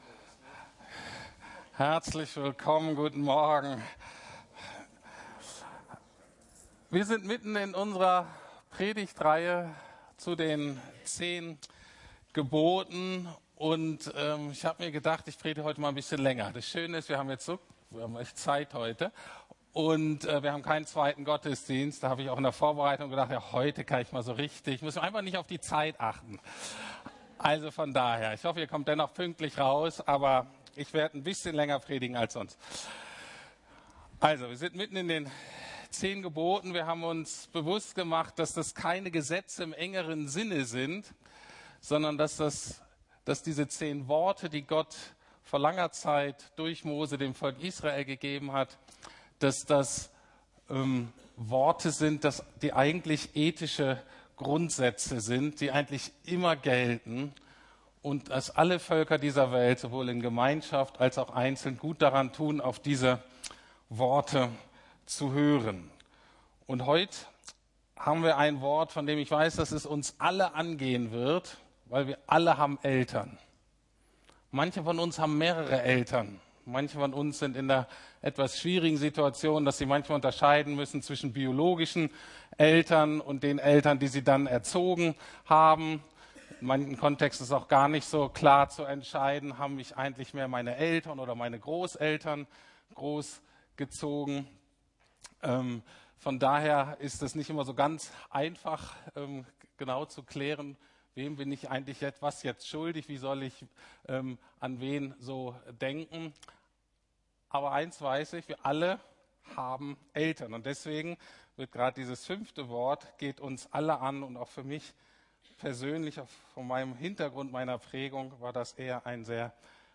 10 Worte des Lebens (Teil 5) ~ Predigten der LUKAS GEMEINDE Podcast